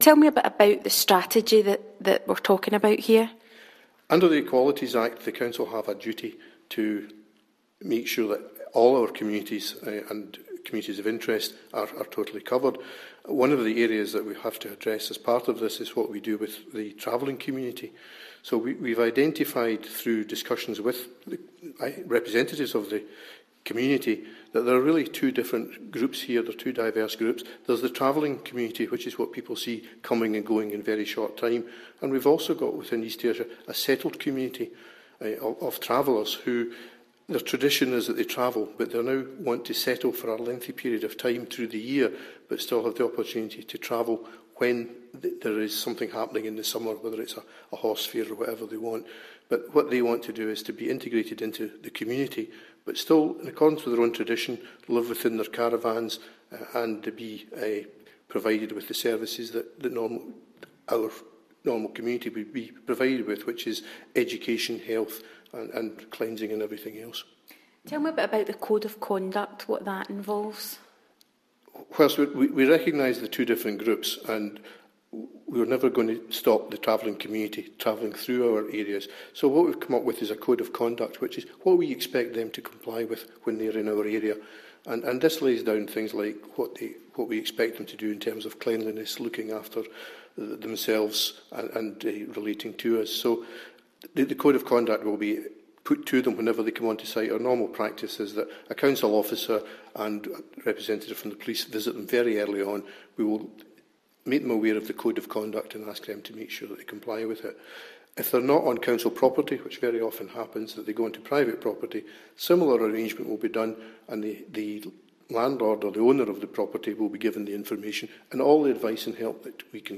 talks to Councillor Tom Cook about travellers